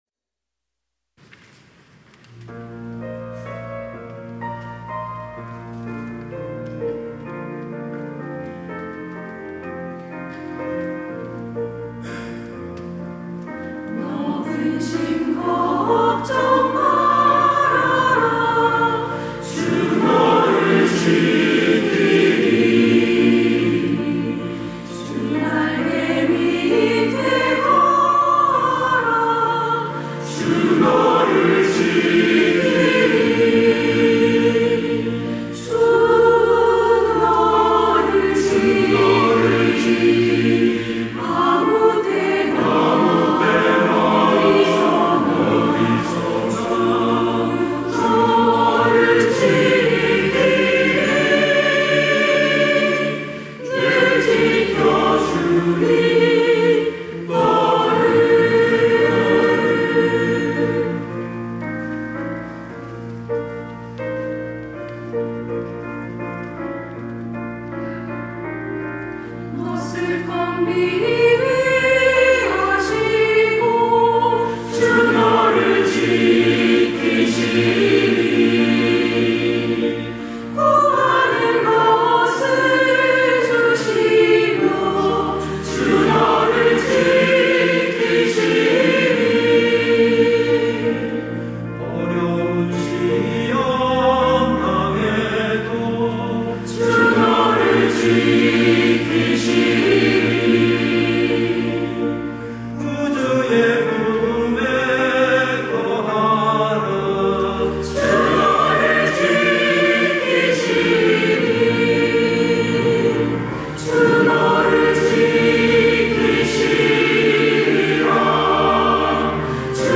시온(주일1부) - 너 근심 걱정 말아라
찬양대